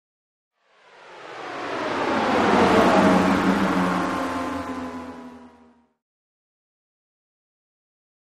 Sweep Electronic Feedback Descending Sweep Wash